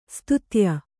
♪ stutya